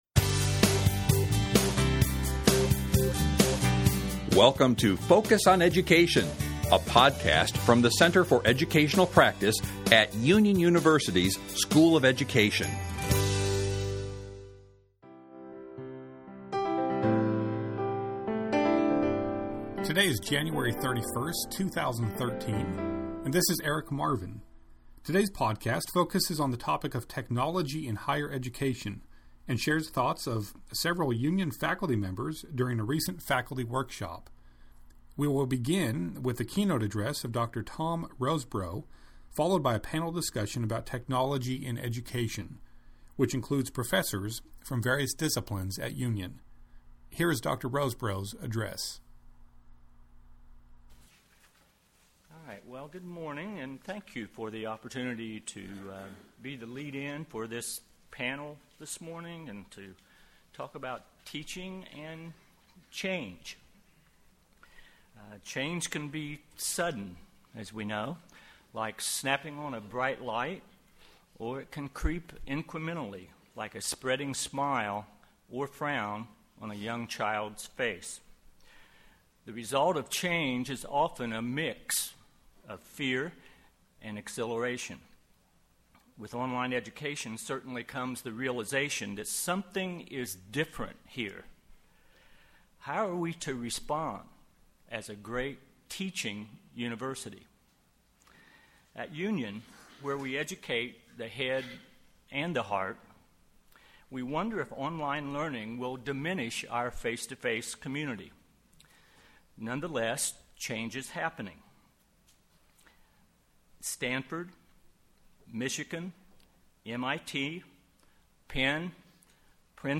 Technology and Higher Education is the topic of this episode, which includes a keynote address and panel discussion about the topic.